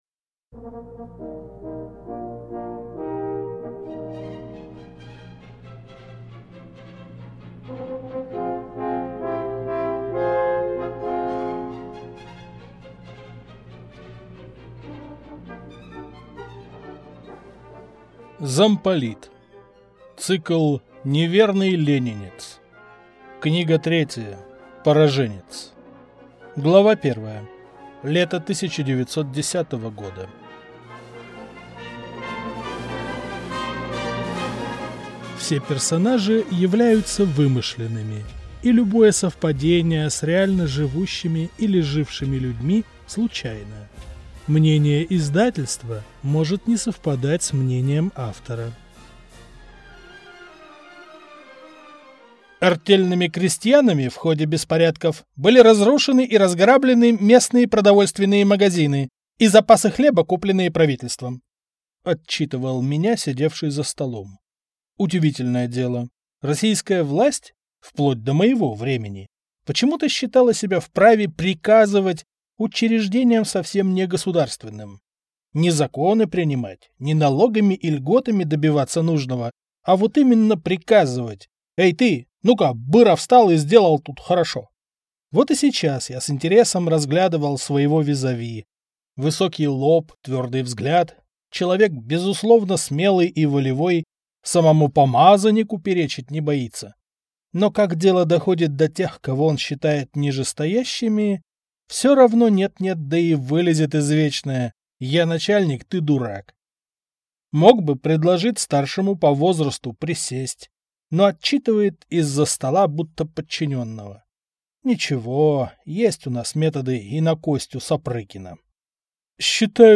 Аудиокнига Пораженец | Библиотека аудиокниг